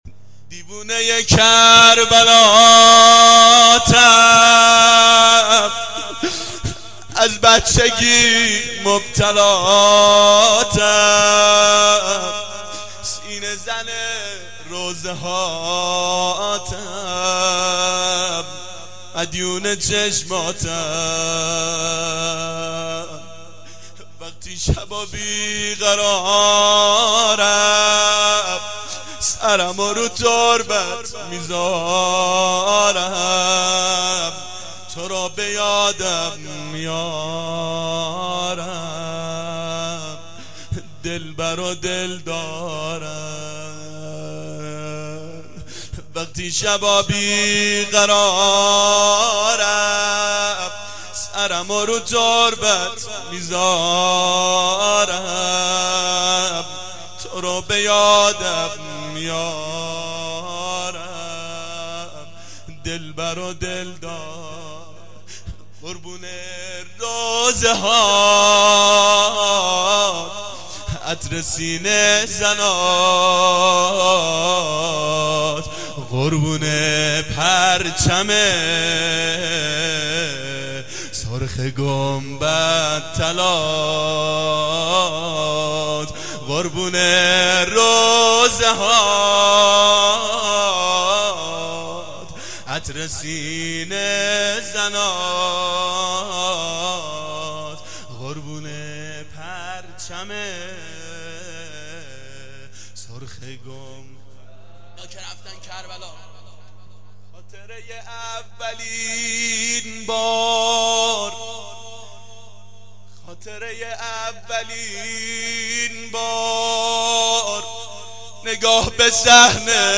شب اول محرم 89 گلزار شهدای شهر اژیه